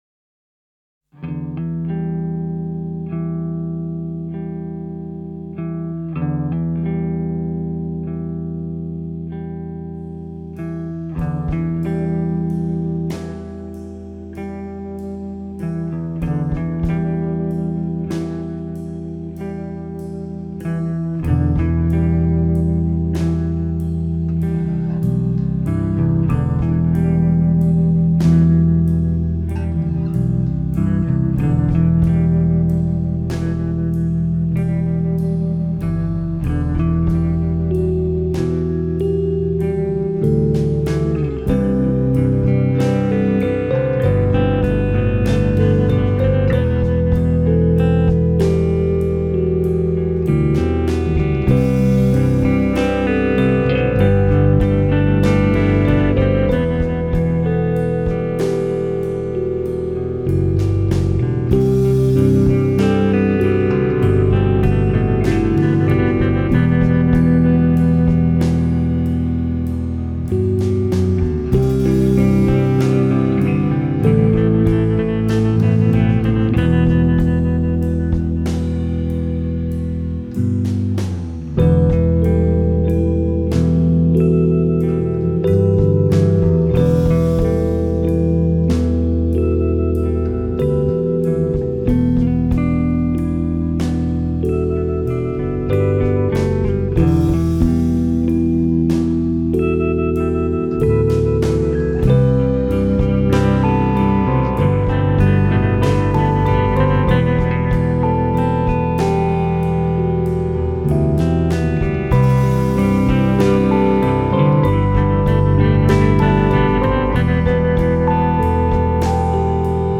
instrumental soundtrack